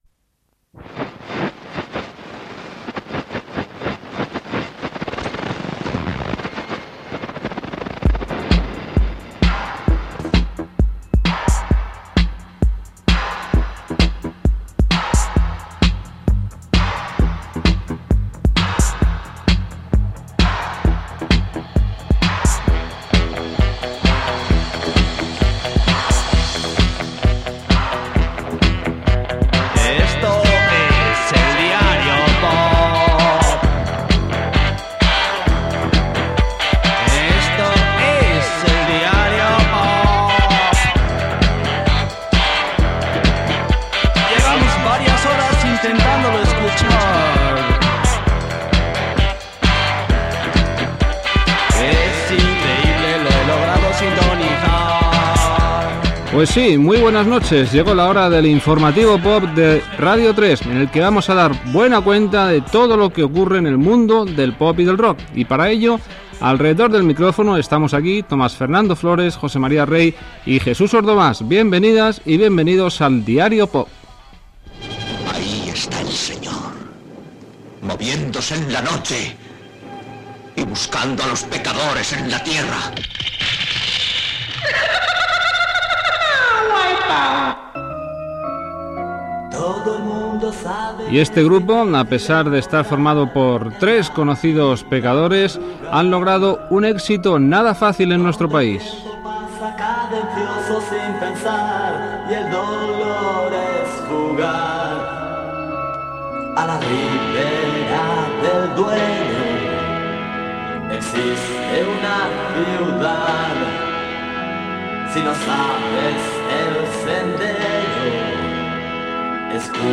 Sintonia cantada del programa, equip i presentació d'un tema musical
Musical